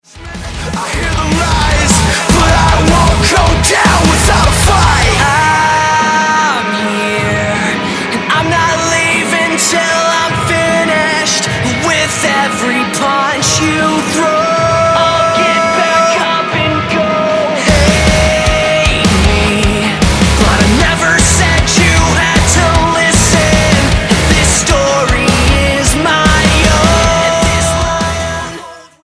melodic hardcore
Style: Hard Music